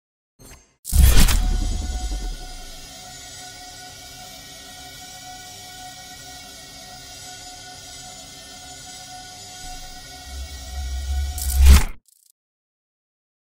На этой странице собраны загадочные звуки порталов — от металлических резонансов до глубоких пространственных эффектов.
Звук портала: открытие и закрытие